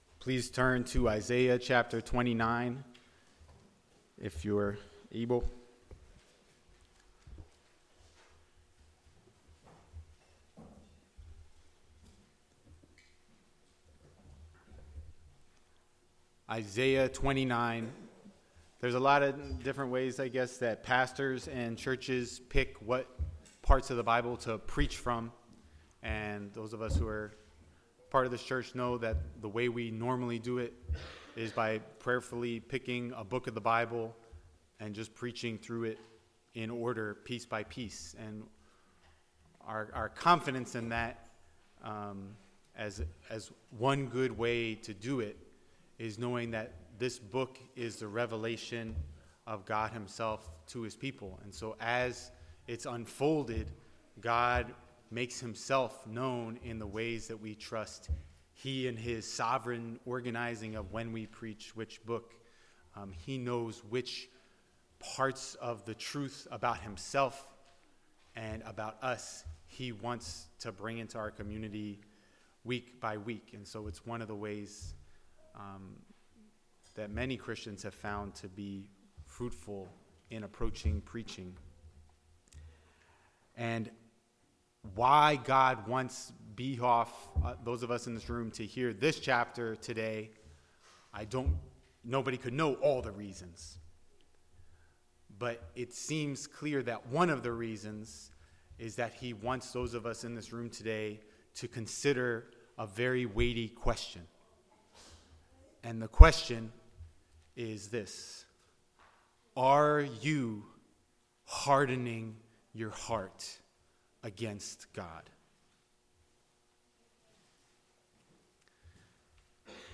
Sermons | The Bronx Household of Faith